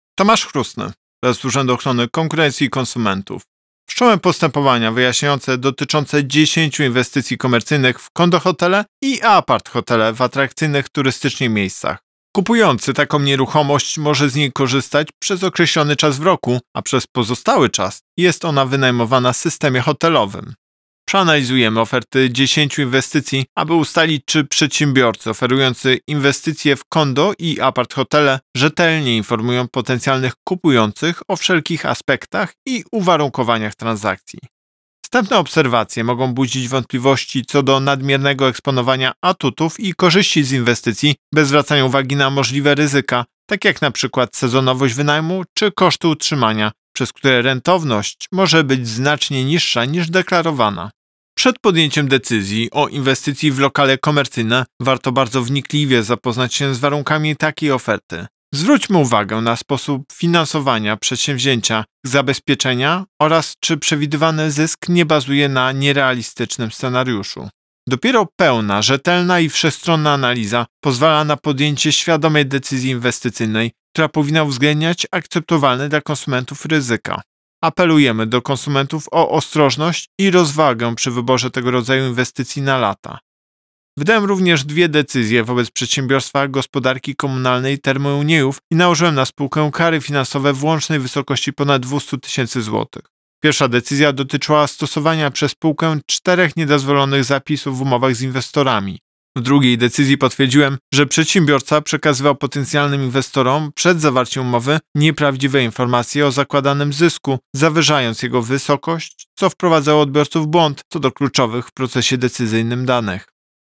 Wypowiedź Prezesa UOKiK Tomasza Chróstnego